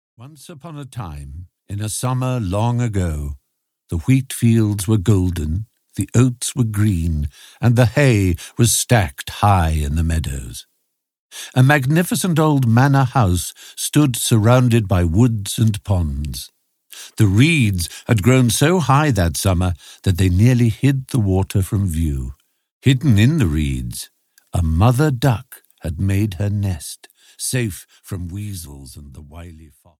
The Ugly Duckling (EN) audiokniha
Stephen Fry reads "The Ugly Ducking", which tells the story of a duckling who is rejected by everyone, including his family, because he looks different.
Ukázka z knihy
• InterpretStephen Fry